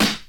• 2000s Smooth Hip-Hop Steel Snare Drum Sample A# Key 01.wav
Royality free snare single hit tuned to the A# note. Loudest frequency: 2681Hz